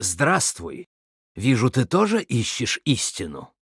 Нам удалось найти в клиенте фразы и локализованную озвучку Кадгара.
Локализованные фразы Кадгара
VO_HERO_08b_GREETINGS_03.wav